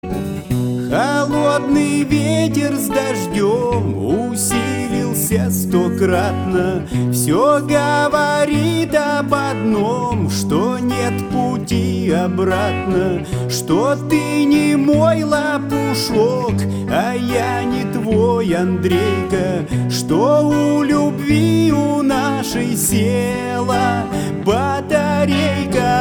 мужской вокал
громкие
грустные
русский рок